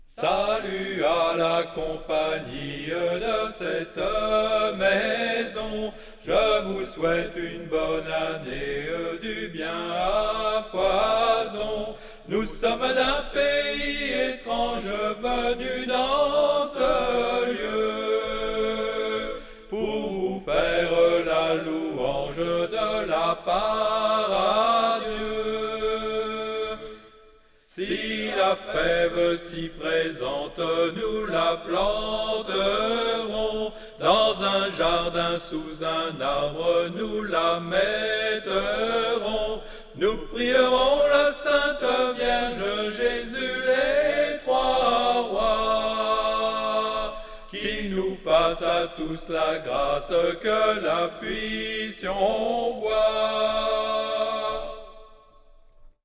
traditionnel
Soprano
à 4 voix